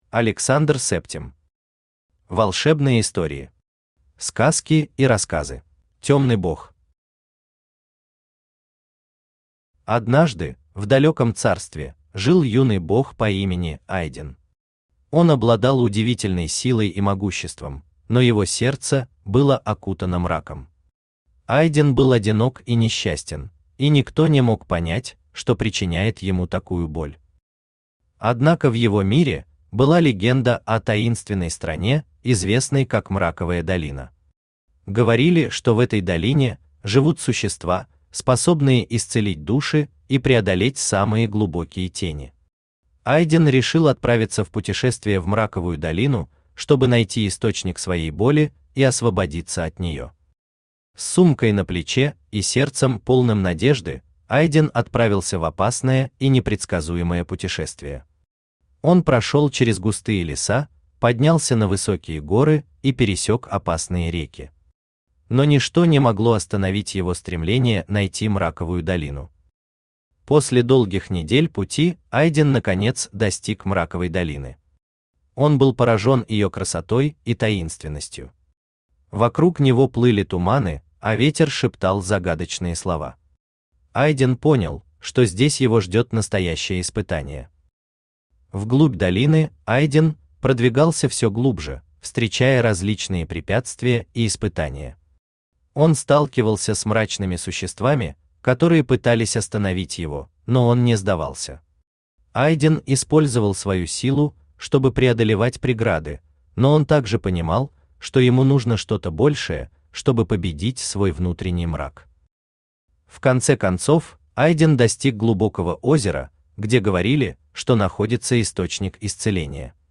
Аудиокнига Волшебные истории. Сказки и рассказы | Библиотека аудиокниг
Сказки и рассказы Автор Александр Септим Читает аудиокнигу Авточтец ЛитРес.